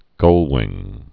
(gŭlwĭng)